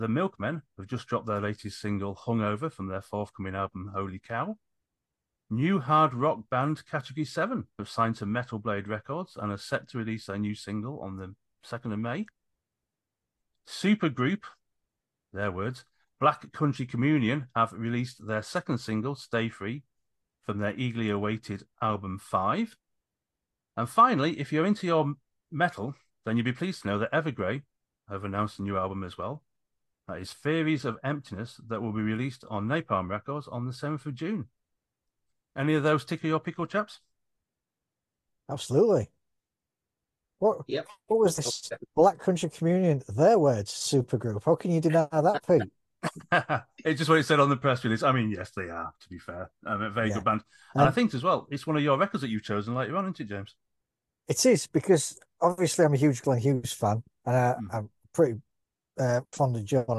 Play Rate Exclusive Listened List Bookmark Share Get this podcast via API From The Podcast Fireworks Pyrotechnics The new music discussion show from Fireworks Rock & Metal Magazine. Want to listen to some real debate about popular Rock and Metal music matters?